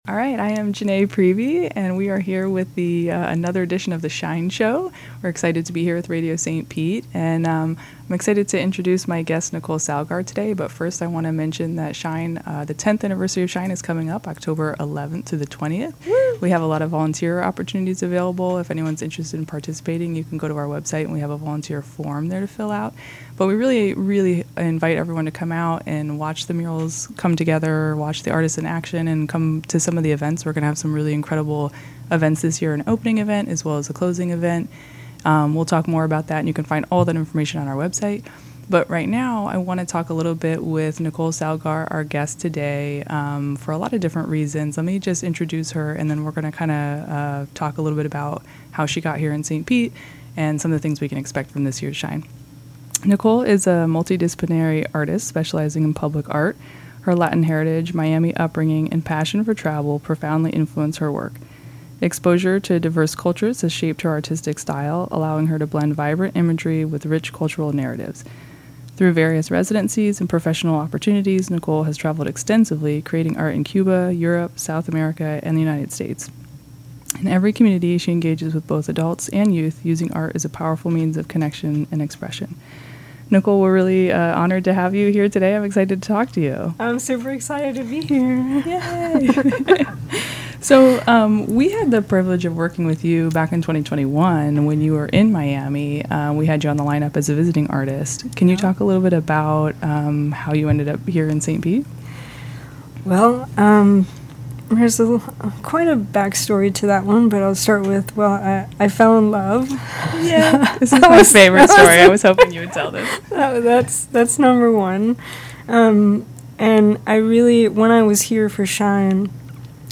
Radio St. Pete Podcast Archive